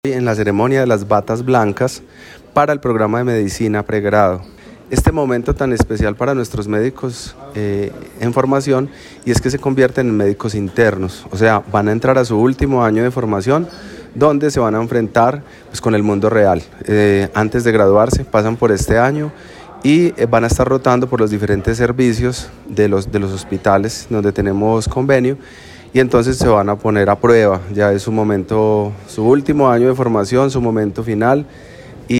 La Universidad Tecnológica de Pereira celebró uno de los actos más simbólicos y emotivos para el programa de Medicina: la entrega de la bata blanca a los estudiantes que inician su año de internado. Este rito de paso marca no solo una transición académica, sino también un compromiso ético profundo con la profesión médica y el servicio a la sociedad.
En un auditorio Jorge Roa Martínez lleno de familiares, docentes y comunidad universitaria, cada estudiante recibió su bata de manos de dos personas significativas de su familia, en un gesto que simbolizó el respaldo emocional que los acompaña en esta etapa decisiva.